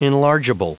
Prononciation du mot enlargeable en anglais (fichier audio)
Prononciation du mot : enlargeable